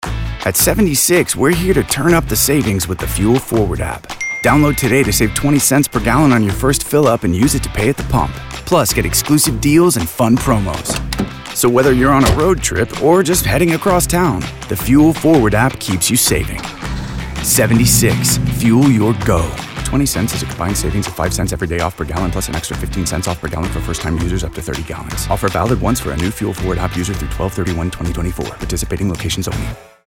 76 Spot